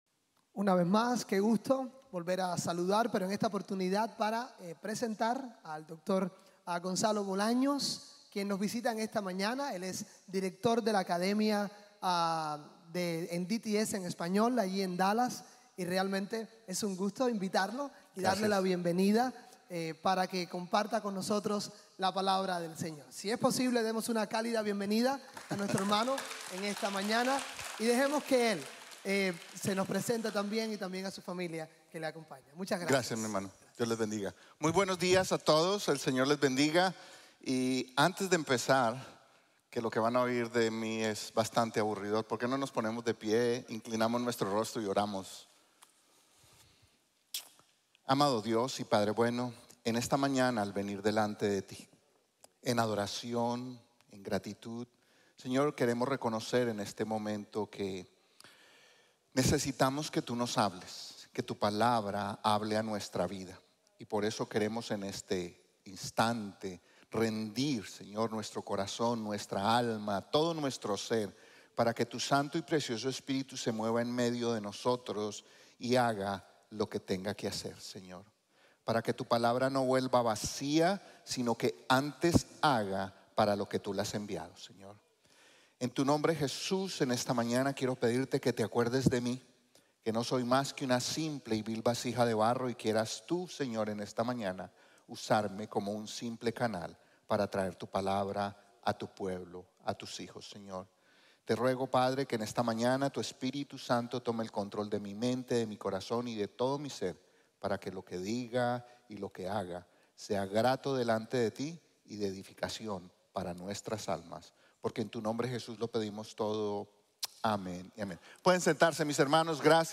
Salmo 27 | Sermon | Grace Bible Church